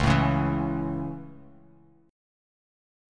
Dbm#5 chord